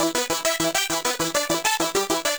Index of /musicradar/8-bit-bonanza-samples/FM Arp Loops
CS_FMArp B_100-E.wav